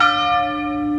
Nous lancerons un son de cloche toutes les cinq secondes.
dong1.mp3